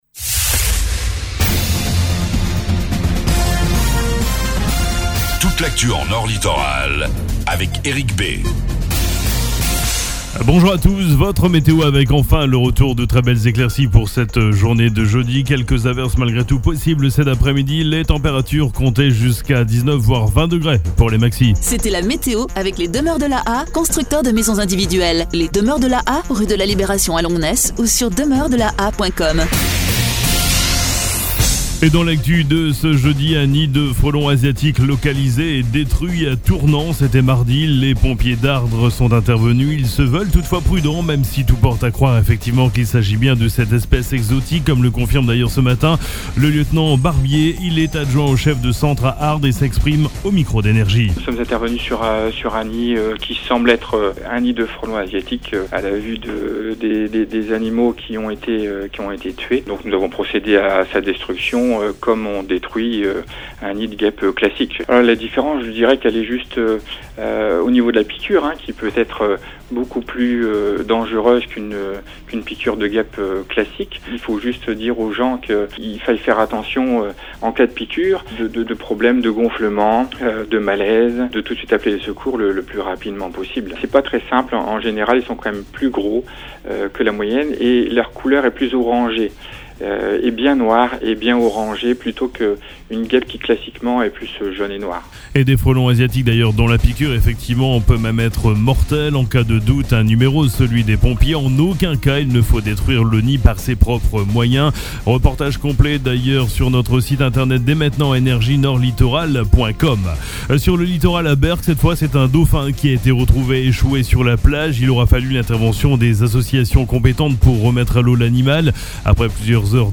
FLASH AUDOMAROIS 30/08